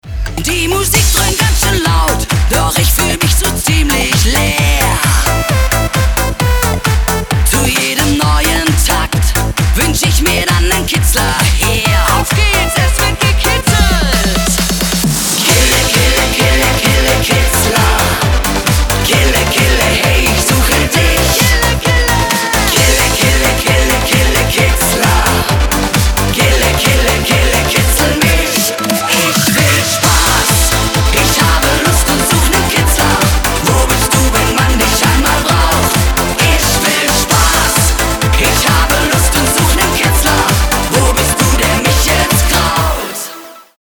Sängerin